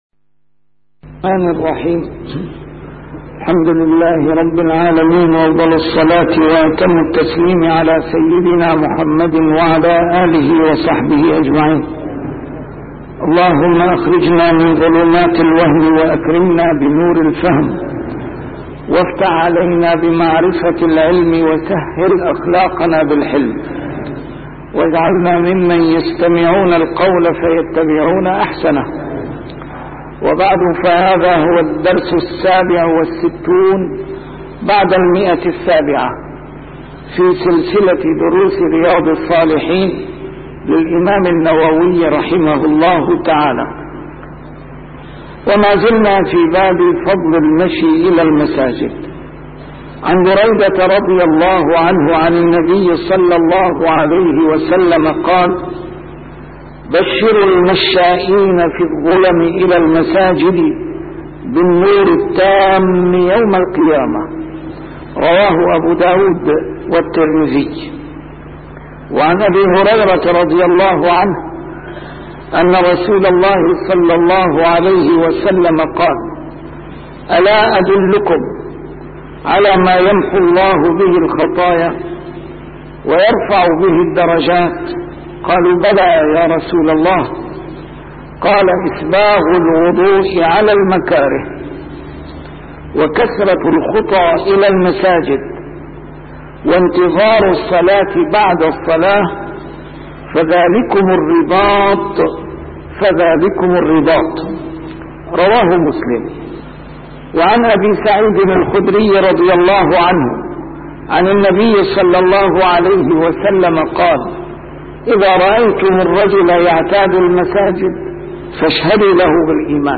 A MARTYR SCHOLAR: IMAM MUHAMMAD SAEED RAMADAN AL-BOUTI - الدروس العلمية - شرح كتاب رياض الصالحين - 767- شرح رياض الصالحين: فضل المشي إلى المساجد